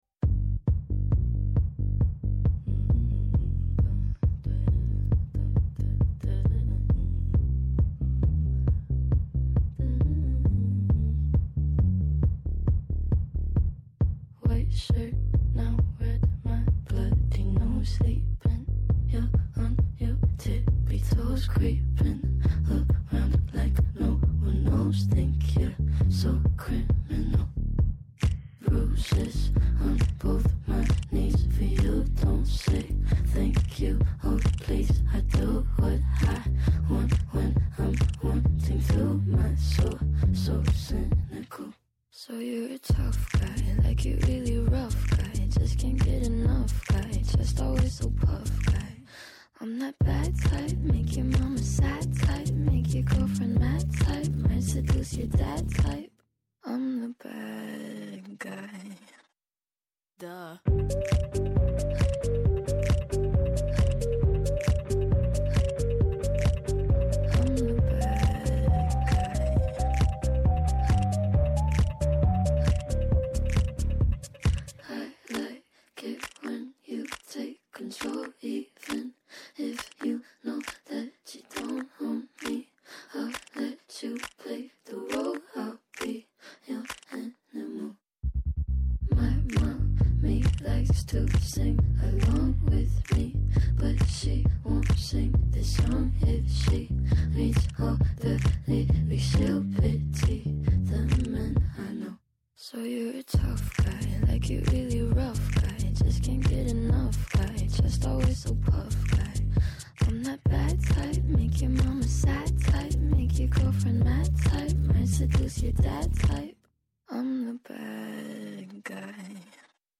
Άνθρωποι της επιστήμης, της ακαδημαϊκής κοινότητας, πολιτικοί, ευρωβουλευτές, εκπρόσωποι Μη Κυβερνητικών Οργανώσεων και της Κοινωνίας των Πολιτών συζητούν για όλα τα τρέχοντα και διηνεκή ζητήματα που απασχολούν τη ζωή όλων μας από την Ελλάδα και την Ευρώπη μέχρι την άκρη του κόσμου.